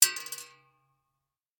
Bullet Shell Sounds
pistol_metal_2.ogg